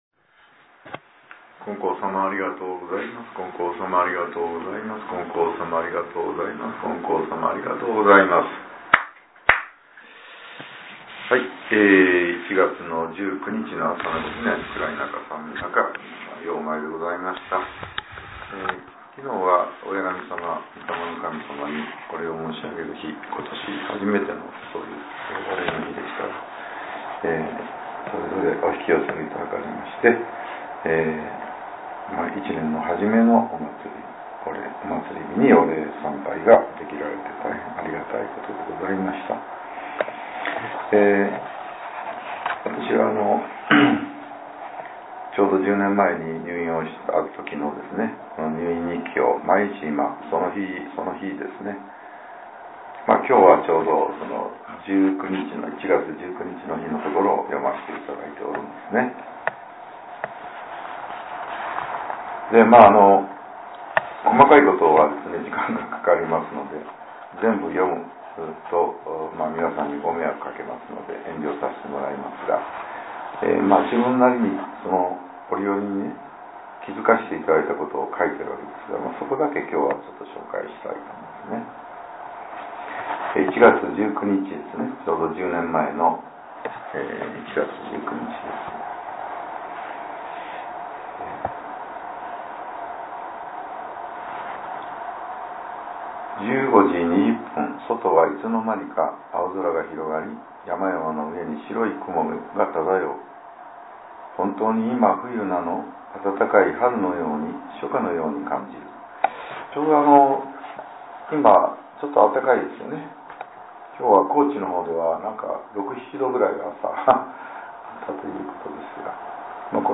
令和８年１月１９日（朝）のお話が、音声ブログとして更新させれています。